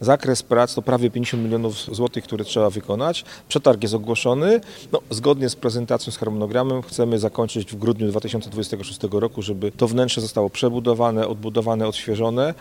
– To inwestycja, która będzie służyć regionowi – mówi Krzysztof Maj, Członek Zarządu Województwa Dolnośląskiego.